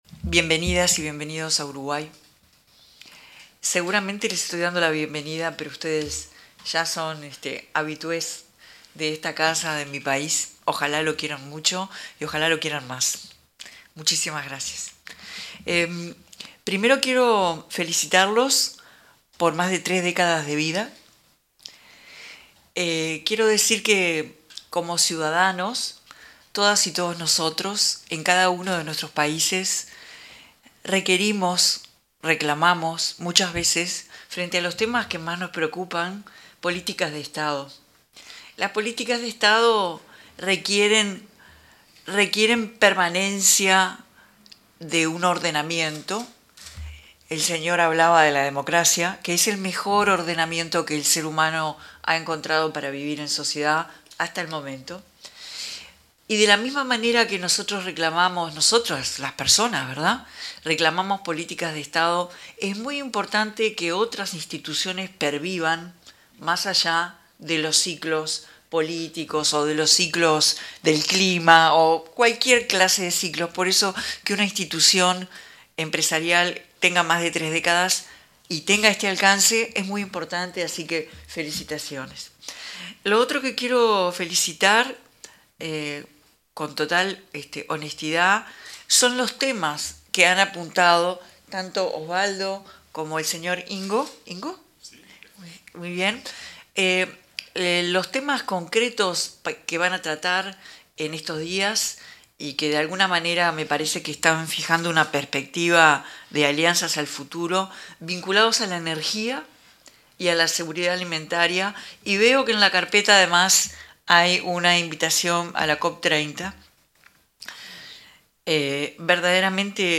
La presidenta de la República en ejercicio, Carolina Cosse, se expresó en la apertura de la actividad Nuevas Perspectivas de la Integración Sur, del